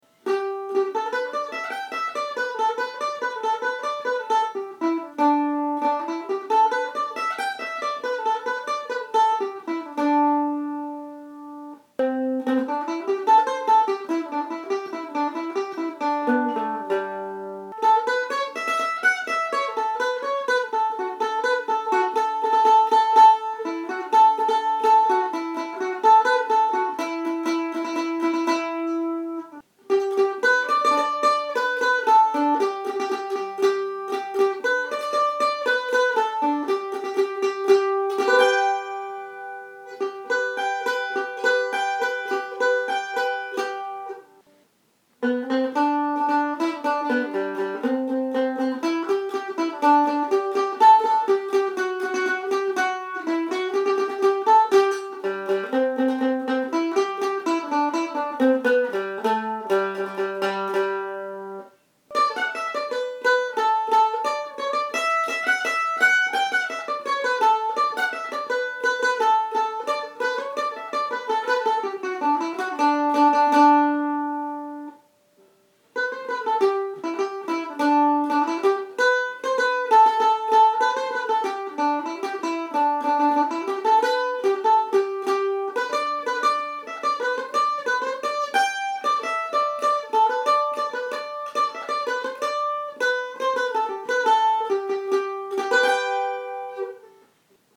A great mandolin for Celtic and old-time music. Chop holds up well in bluegrass settings as well. 48 hour approval period on purchase.
Very sweet sound.